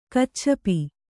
♪ kacchapi